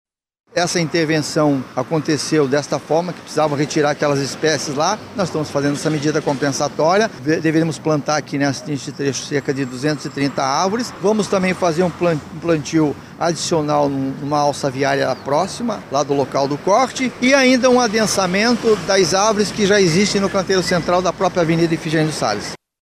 Como explica o chefe da pasta, Antônio Stroski.
Sonora-1-Antonio-Stroski-–-secretario-da-Semmas.mp3